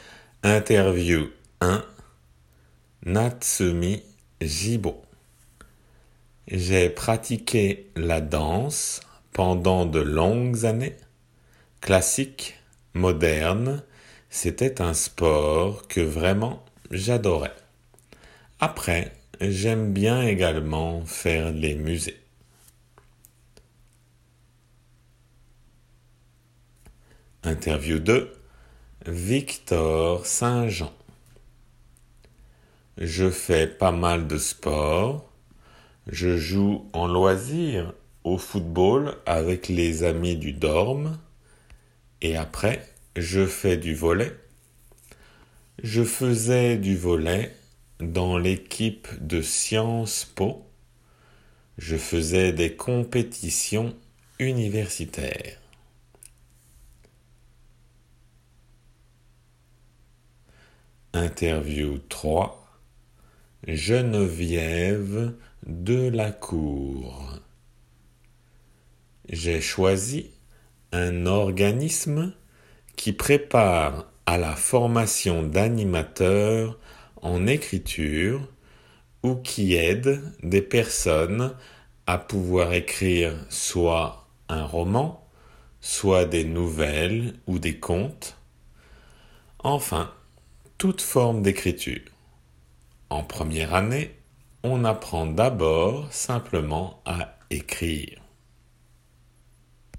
３人の人にインタビューをしています。